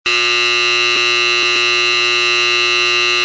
Sound Effects
Loud Buzzer